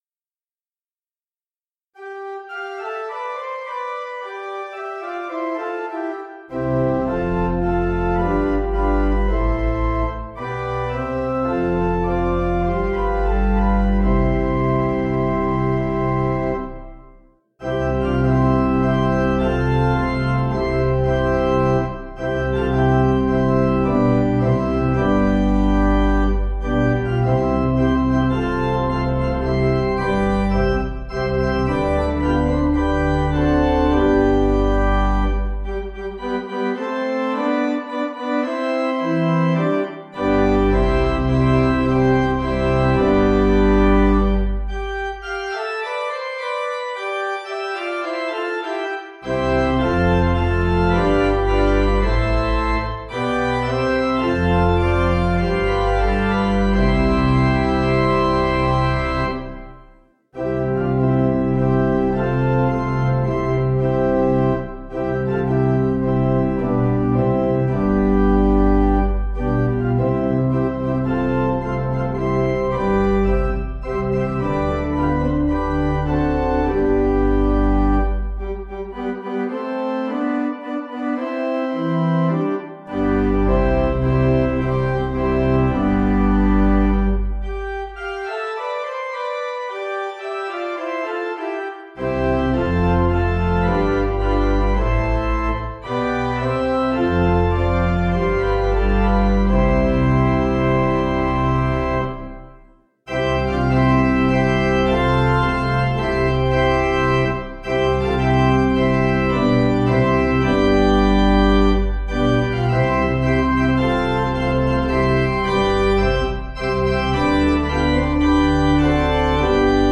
Sunday School
Organ